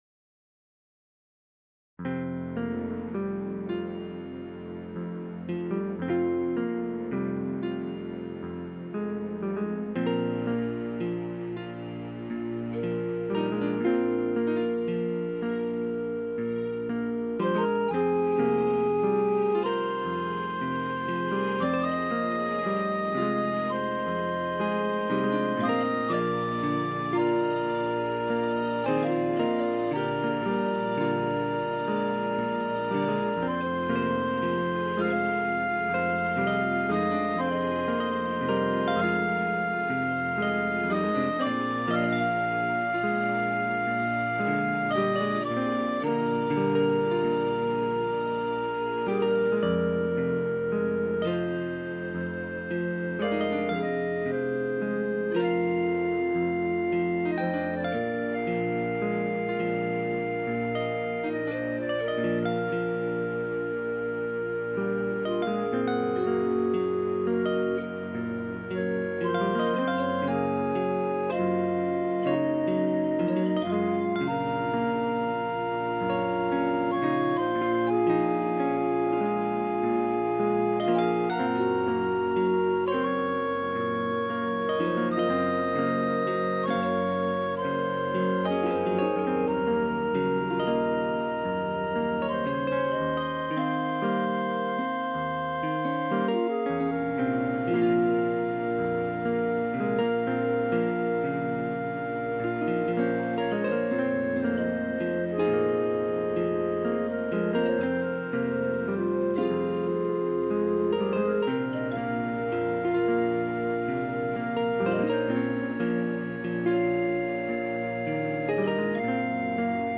An interesting tune, but a bit messed at the end.